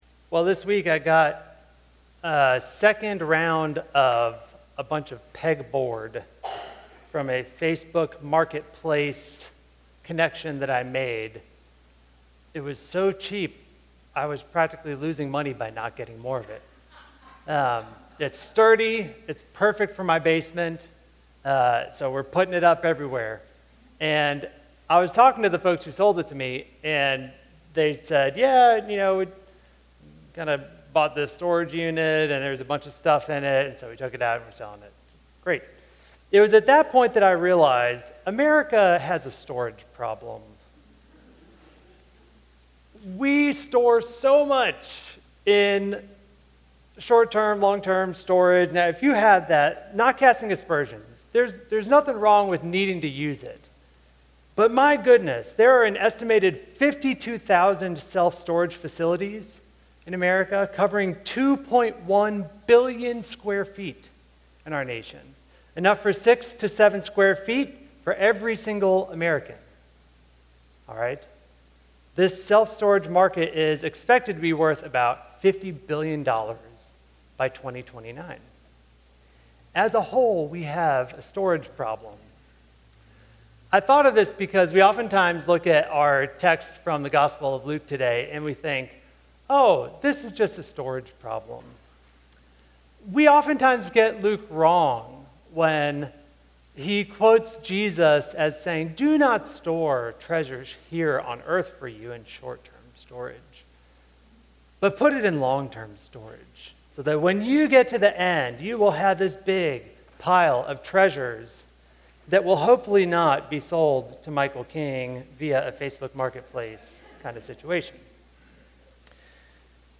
message from Sunday, August 3, 2025.